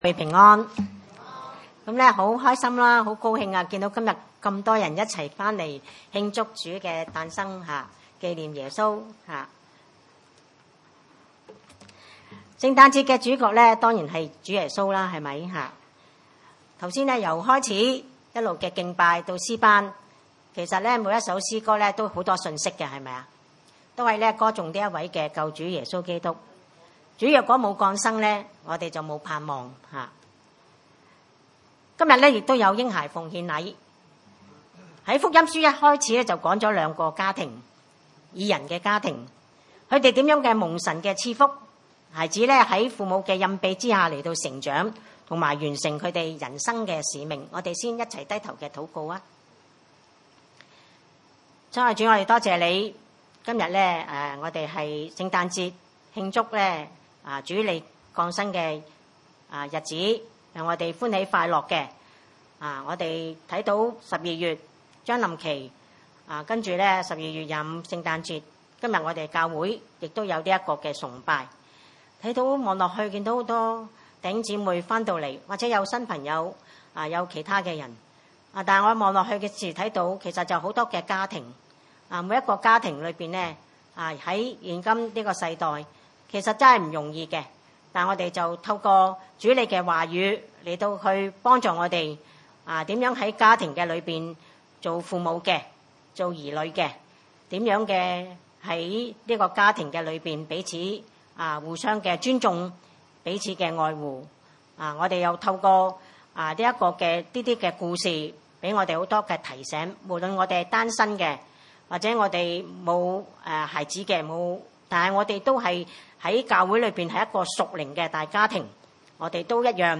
經文: 路加福音 2：21-24, 41-46 崇拜類別: 主日午堂崇拜 21 滿了八天、就給孩子行割禮、與他起名叫耶穌、這就是沒有成胎以前、天使所起的名。